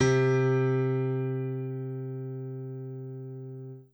BAL Piano C2.wav